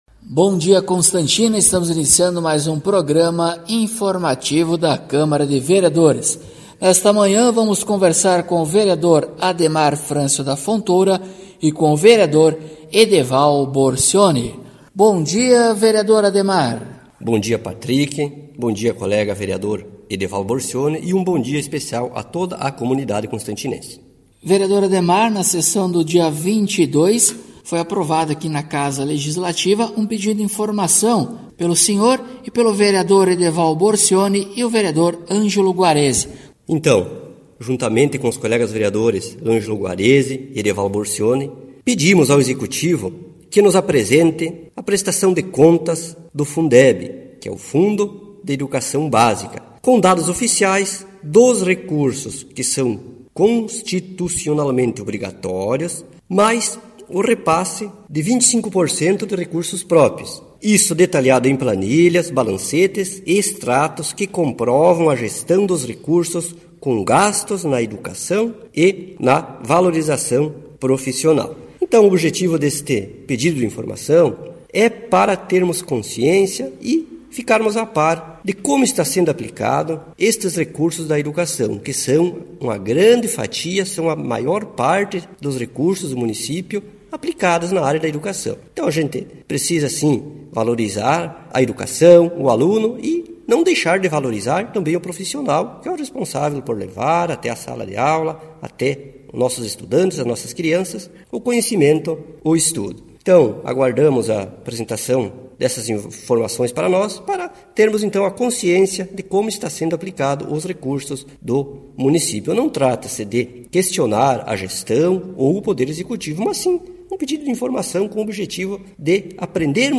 Acompanhe o programa informativo da câmara de vereadores de Constantina com o Vereador Ademar Francio da Fontoura e o Vereador Edeval Borcioni.